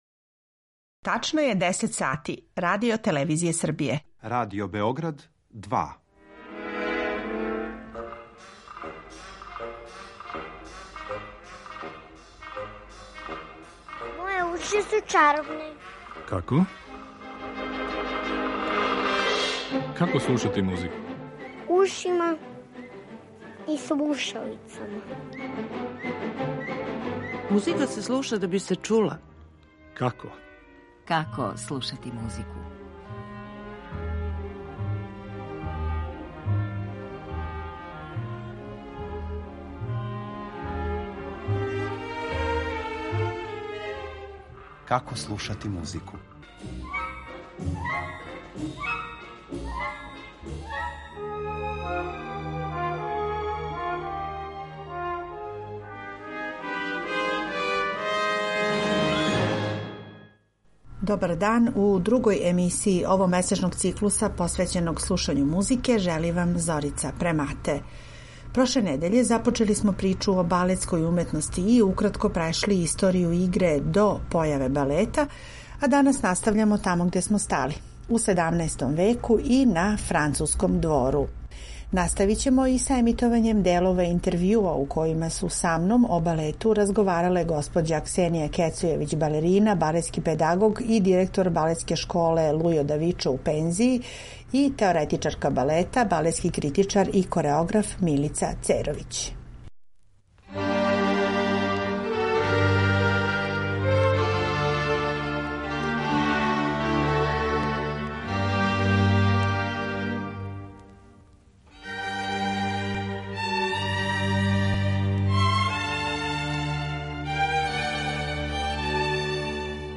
У емисији ћете имати прилику и да чујете одломке мање познатих, као и чувених балета који су обележили историју овог жанра у музици.